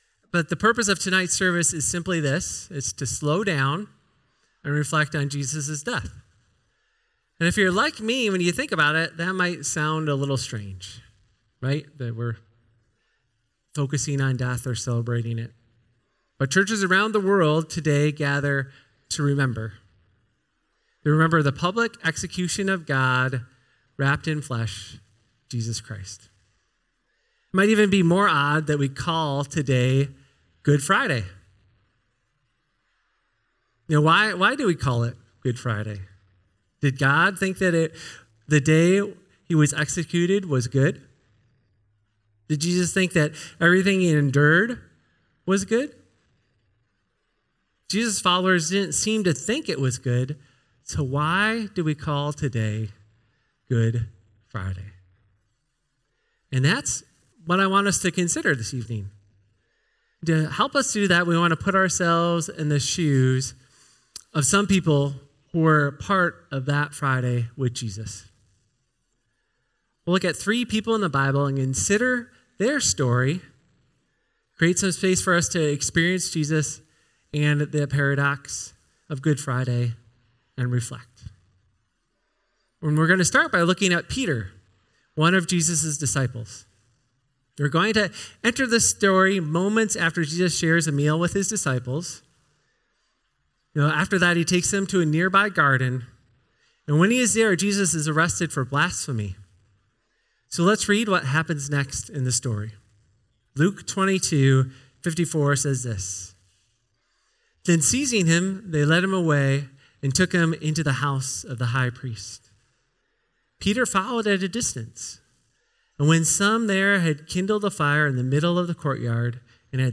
In this Good Friday service, we are invited to slow down and sit within the uncomfortable tension of the crucifixion. By stepping into the shoes of three biblical figures—Peter, who faced the gaze of love in the midst of his deepest shame; Barabbas, the revolutionary who walked free because Jesus took his place; and Mary, who stood at the foot of the cross when the promises of God seemed to be crumbling—we explore why the public execution of a Savior is ultimately called "Good."